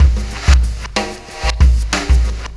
Black Hole Beat 05.wav